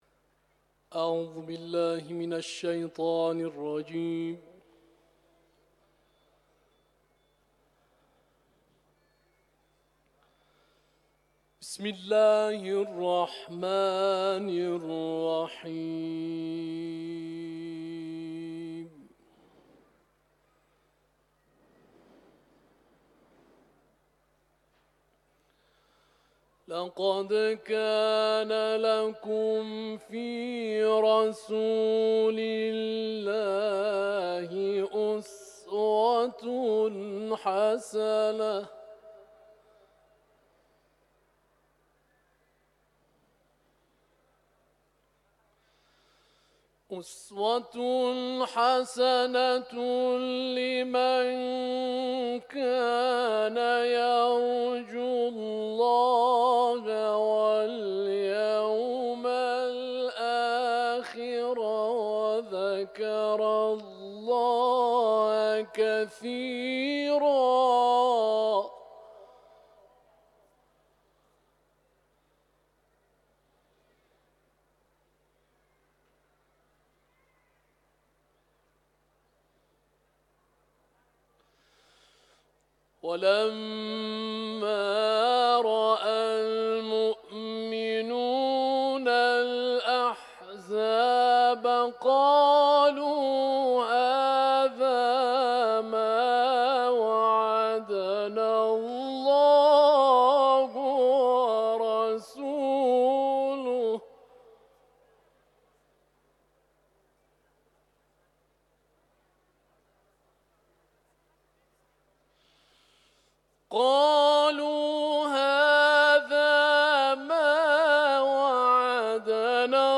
تلاوت
حرم مطهر رضوی ، سوره احزاب